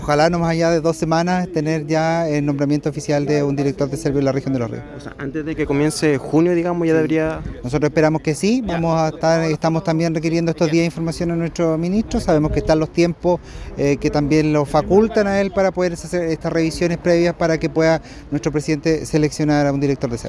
Así lo indicó el Seremi de Vivienda de Los Ríos, Daniel Barrientos, a La Radio, aseverando que dentro de dos semanas se definiría el nombre con base en la terna entregada al Presidente Gabriel Boric.